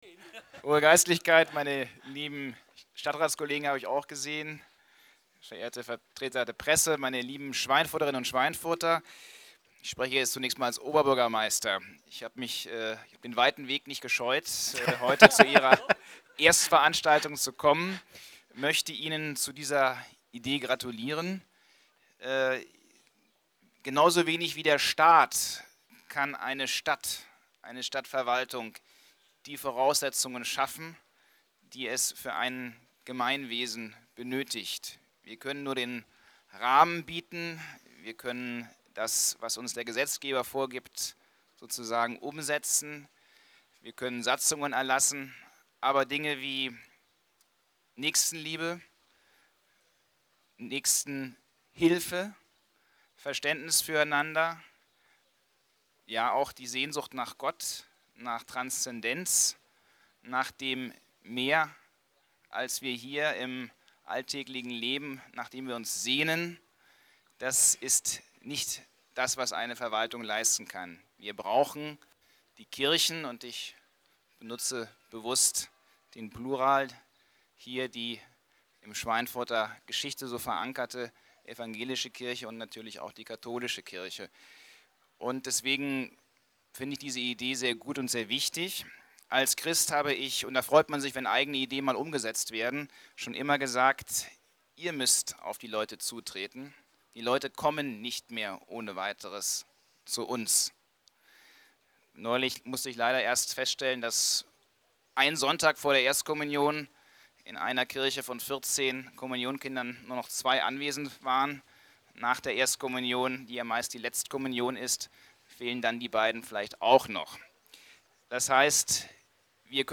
Wagenkirche: Grußwort von OB Sebastian Remelé